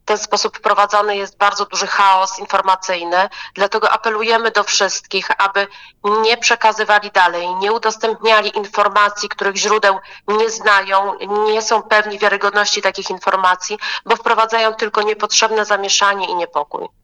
Przed ich rozpowszechnianiem przestrzega podinsp.